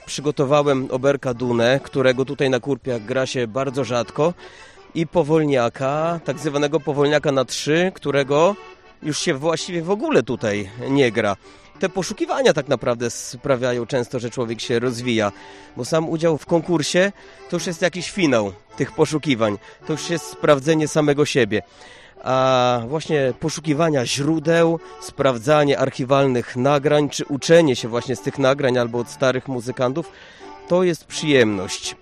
Na scenie amfiteatru w Dobrym Lesie w gminie Zbójna zaplanowano 56 występów.